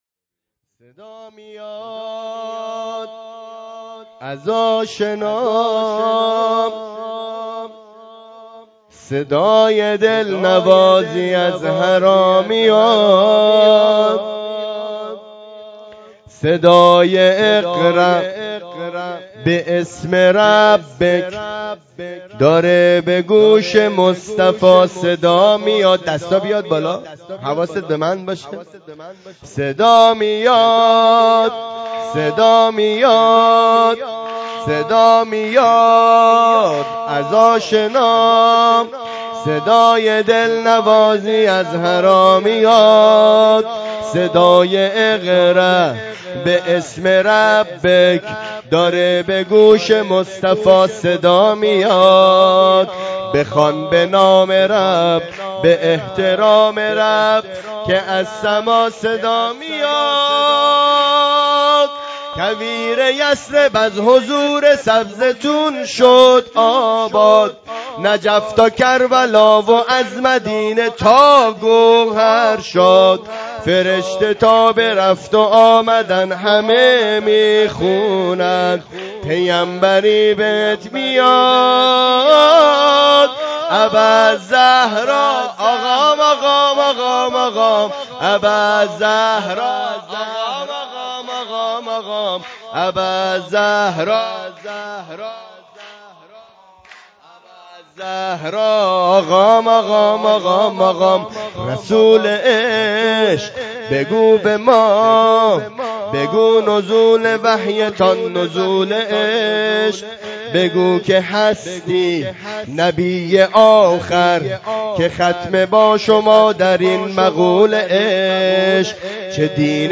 جشن بزرگ مبعث رسول مکرم اسلام1403